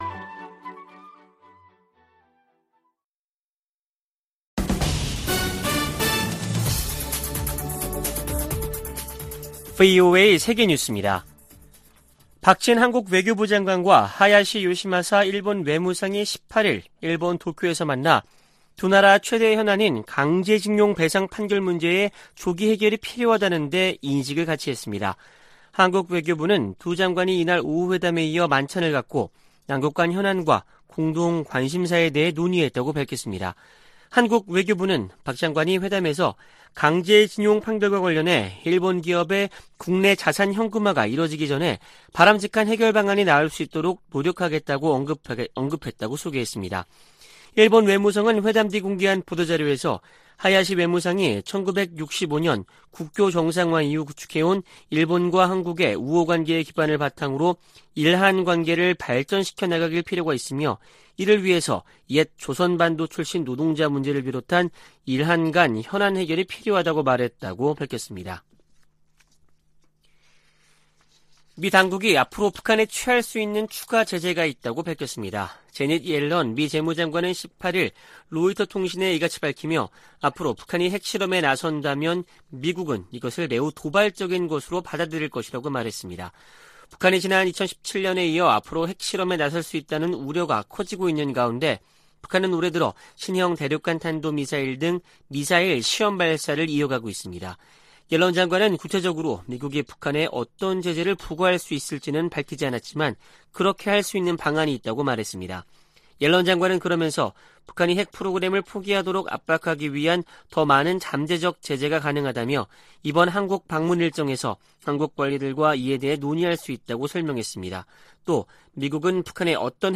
VOA 한국어 아침 뉴스 프로그램 '워싱턴 뉴스 광장' 2022년 7월 19일 방송입니다. 미 국무부 고위 관리가 정보기술(IT) 분야에 위장 취업한 북한인들과 제3국인들이 제기하는 문제와 위험성을 경고했습니다. 워싱턴 전문가들은 북한의 제재 회피를 방조하는 중국과 러시아를 제재할 필요가 있다고 강조했습니다. 주한미군 규모를 현 수준으로 유지하는 새 회계연도 국방수권법안을 미 하원이 최종 의결했습니다.